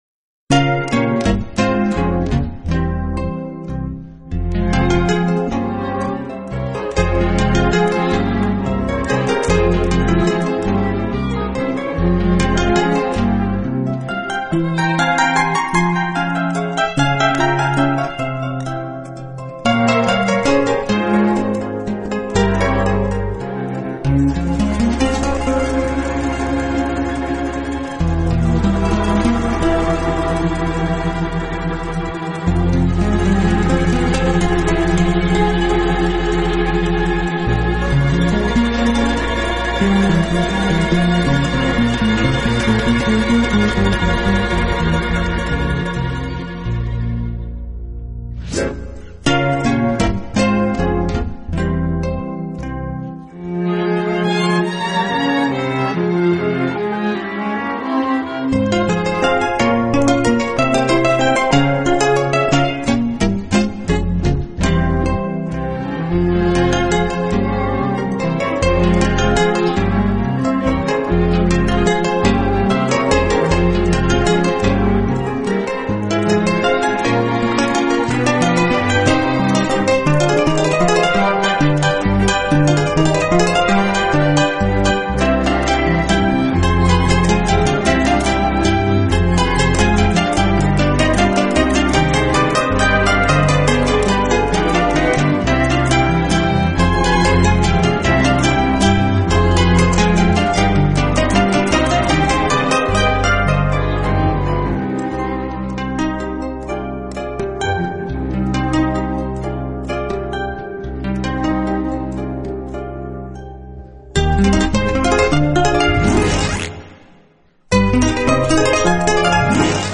音乐类型：NewAge 新世纪
音乐风格：器乐